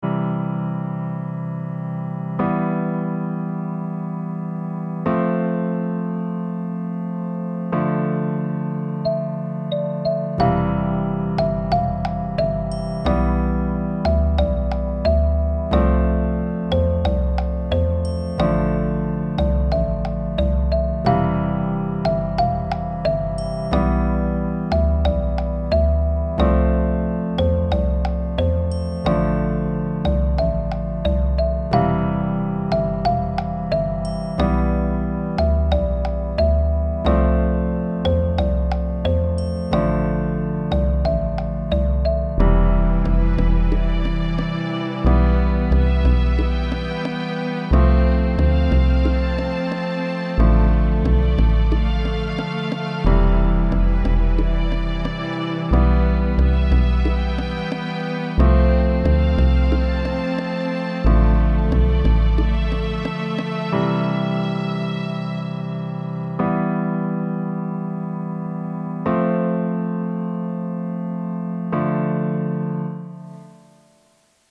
This is BGM for telling a story